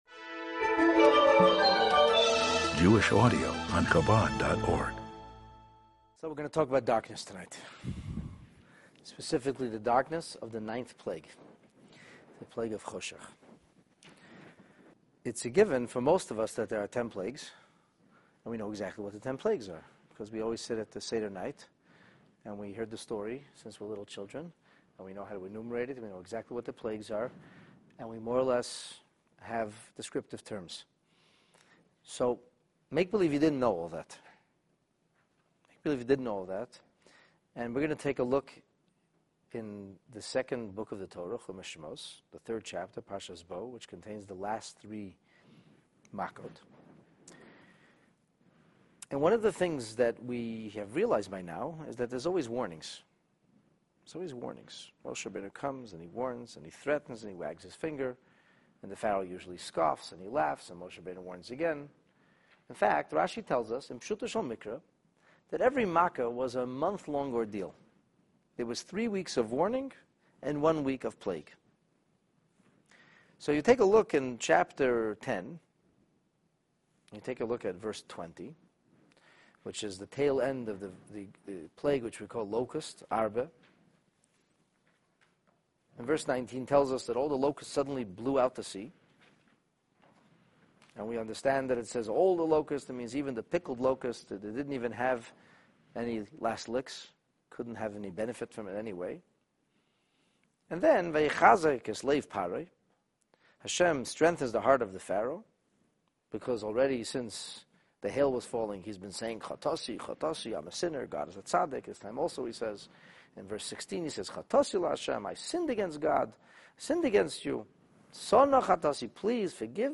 The Ten Plagues: 9 Darkness (Choshech) The plague of darkness was incredibly dense and intense for the Egyptians, while the Israelite nation enjoyed a miraculously bright and lit-up environment. This class will shed light and reveal obscure facets of this remarkable plague, and the layers of purpose it served in preparation for the Exodus.